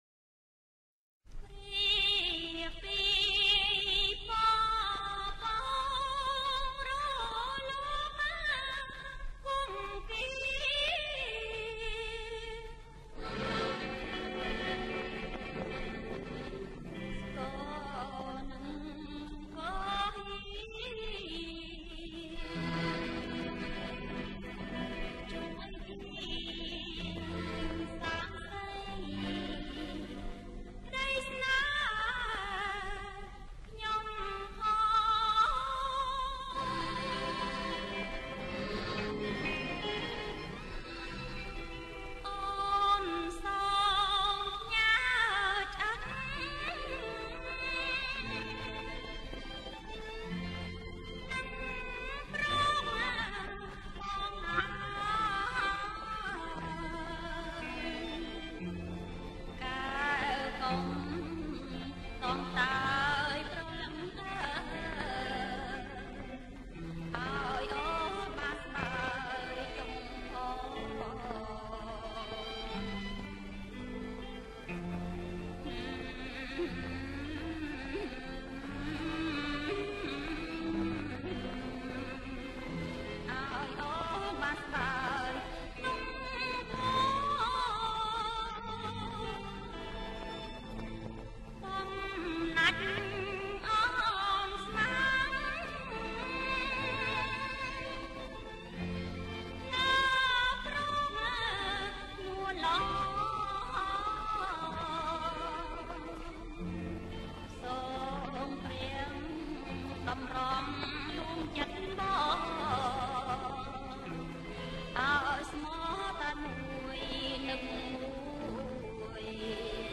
ប្រគំជាចង្វាក់  Slow Folk Long